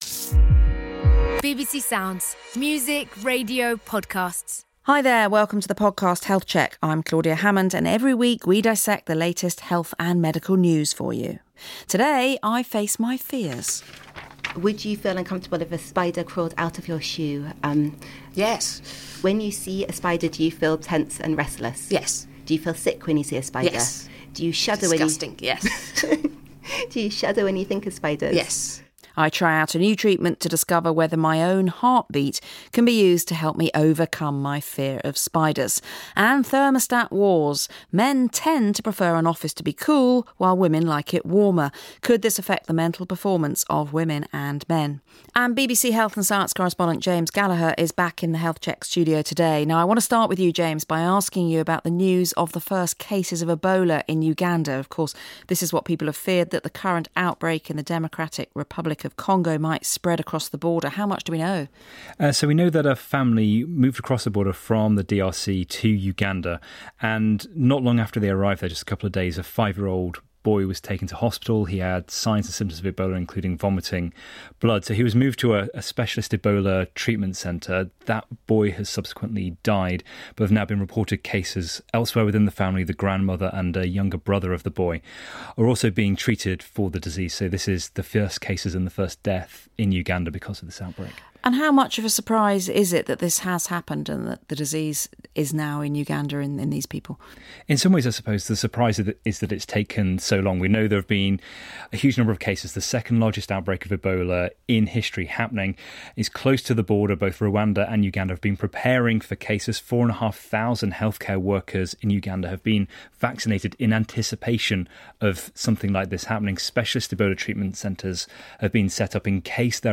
接受了BBC健康检查播客的采访